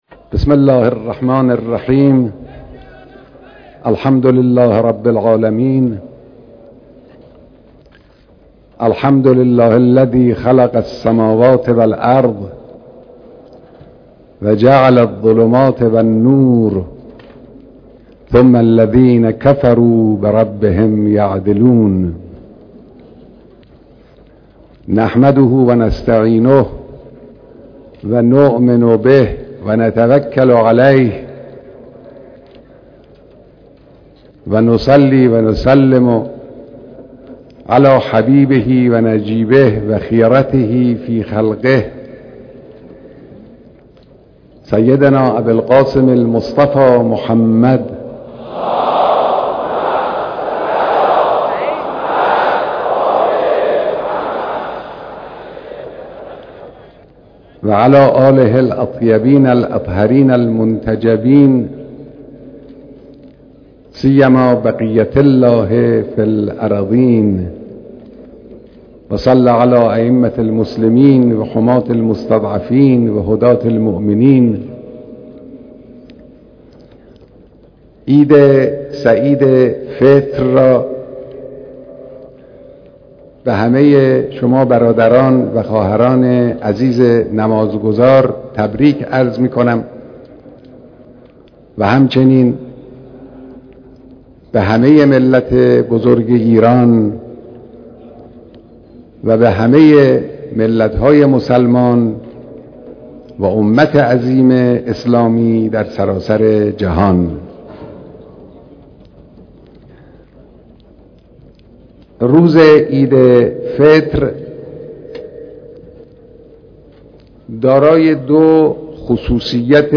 خطبه هاي نماز عید سعید فطر